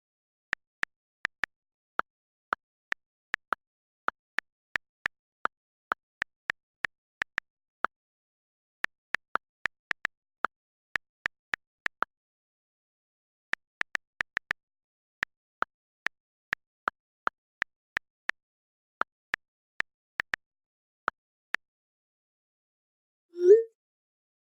صدای کیبورد گوشی
صدای-کیبورد-گوشی.mp3